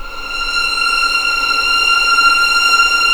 F6LEGPVLN  L.wav